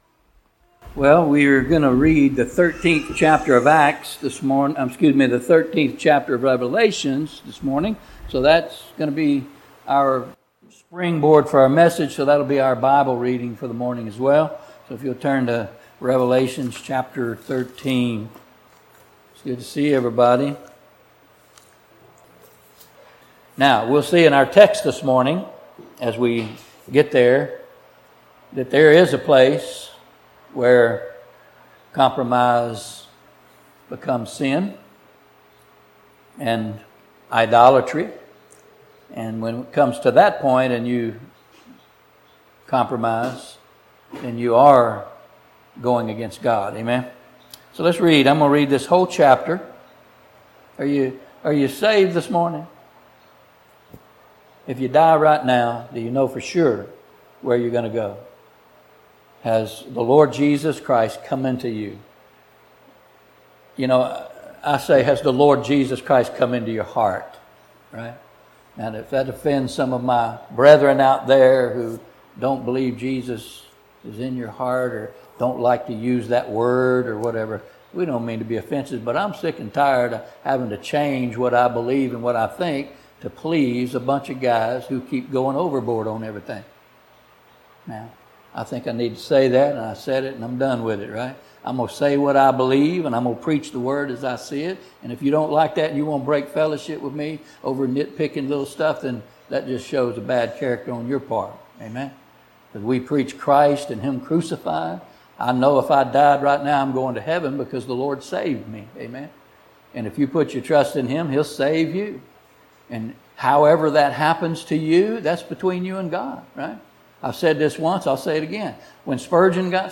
The image and the mark of the beast Our apologies, the video was cut off before it was done due to internet issues. The audio also was cut a few minutes before the end of the sermon by mistake, but the audio has more than the video.
Service Type: Sunday Morning